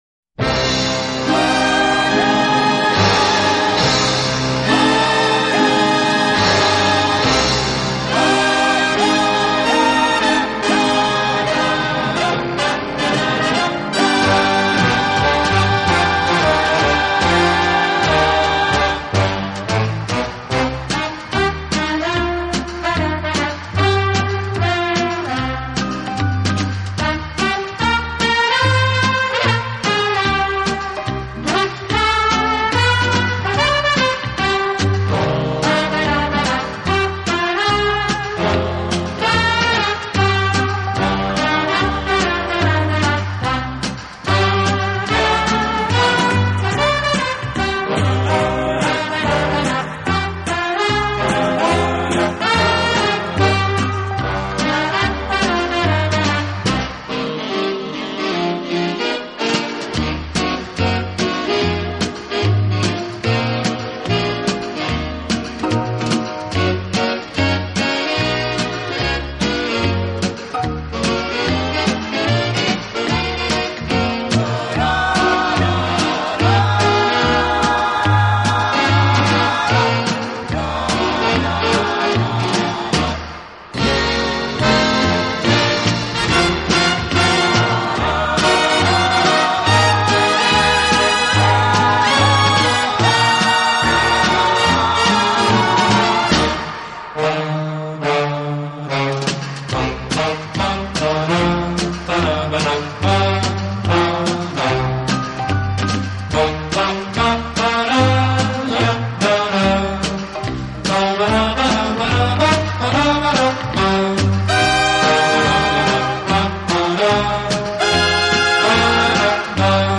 【轻音乐专辑】
他在60年代以男女混声的轻快合唱，配上轻松的乐队伴奏，翻唱了无数热